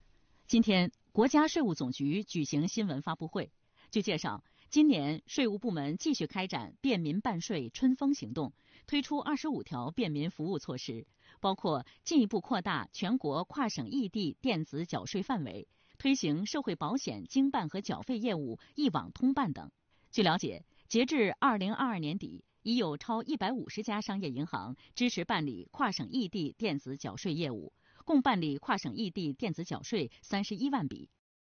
2月21日，国家税务总局召开新闻发布会，介绍了2023年“便民办税春风行动”接续推出的第二批25条便民服务措施以及“一带一路”税收征管合作机制推出的新举措等相关内容。